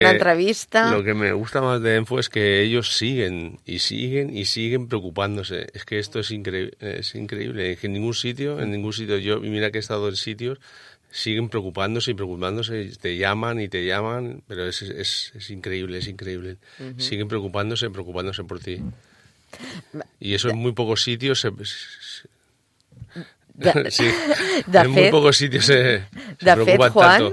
Per això volem compartir amb vosaltres un tall de veu del programa de ràdio “